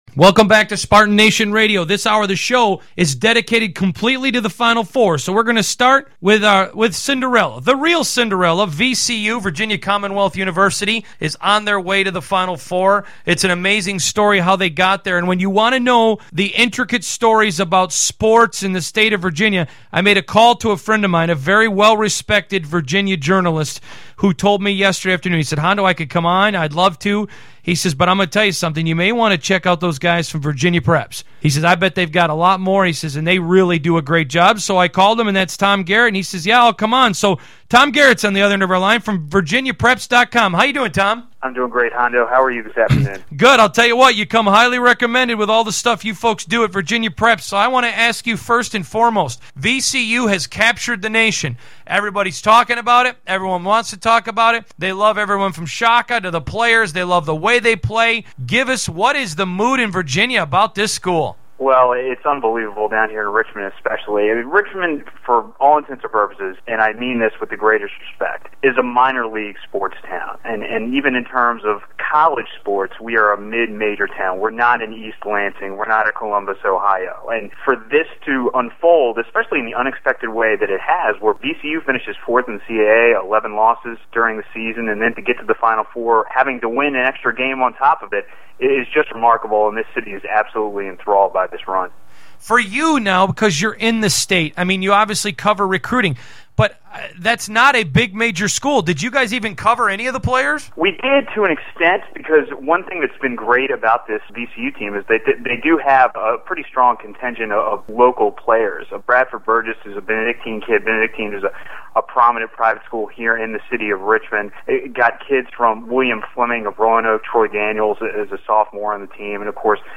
We covered a fairly wide range of VCU-related information in the interview.